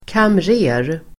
Ladda ner uttalet
kamrer substantiv, (senior) accountant Uttal: [kamr'e:r] Böjningar: kamreren, kamrerer Definition: chef för bokföringen och kassan på ett företag; avdelningschef på en bank Sammansättningar: bankkamrer ([bank] branch manager)